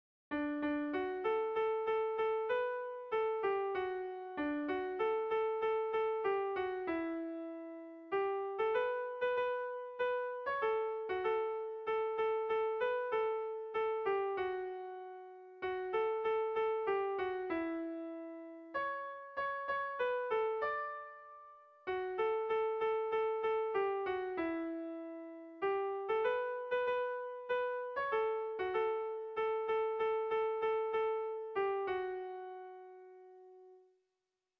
Haurrentzakoa
Zortziko handia (hg) / Lau puntuko handia (ip)
ABDB